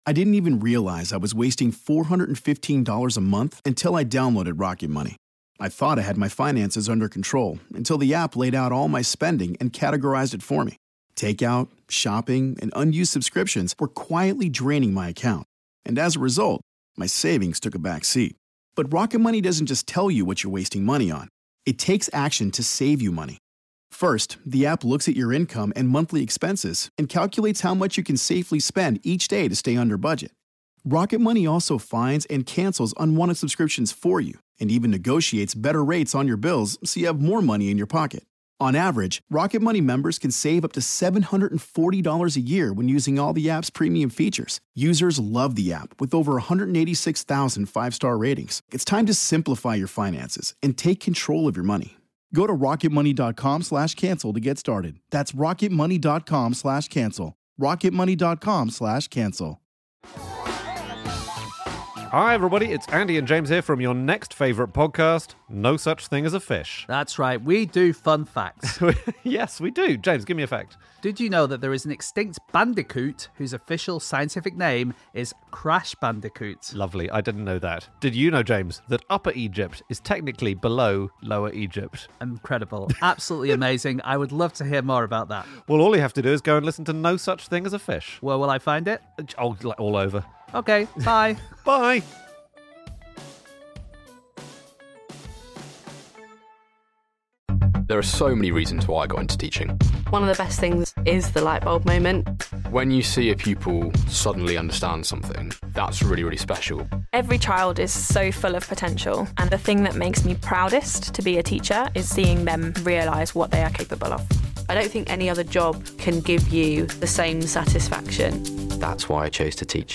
Roundtable discussion about Ubisoft's E3 2015 press conference